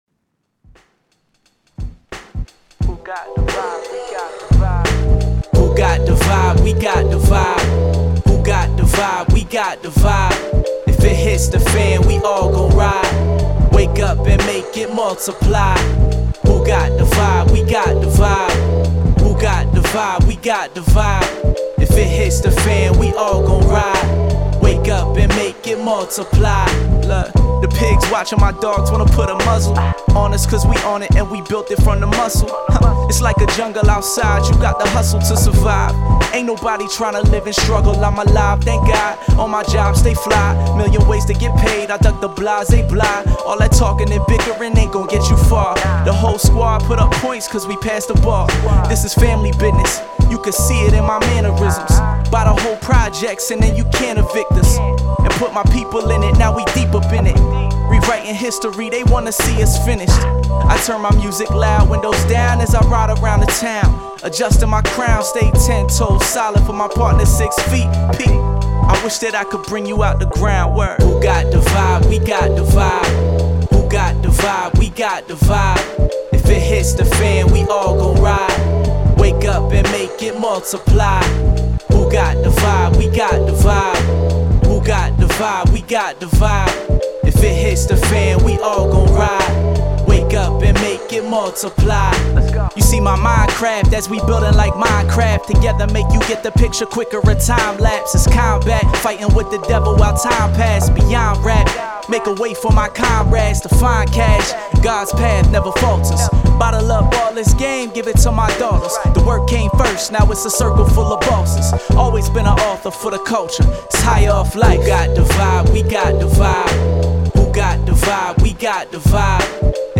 Hip Hop, 90s
G Major